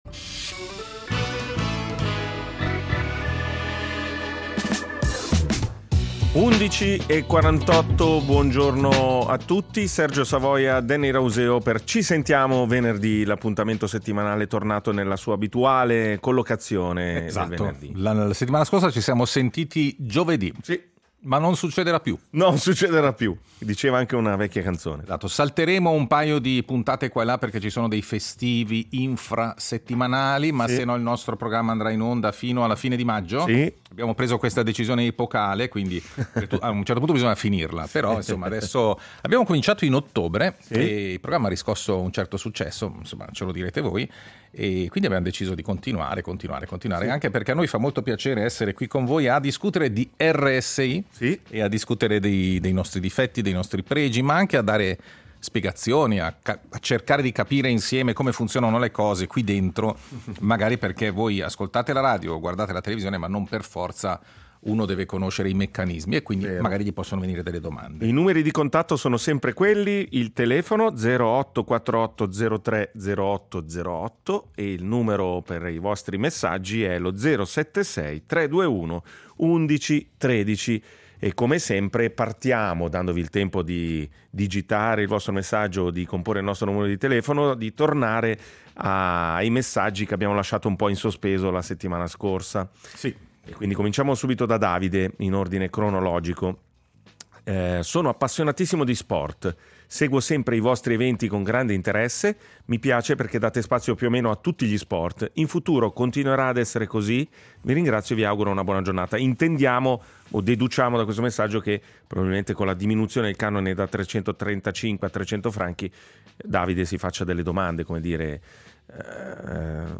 Il tavolo radiofonico dove ci si parla e ci si ascolta.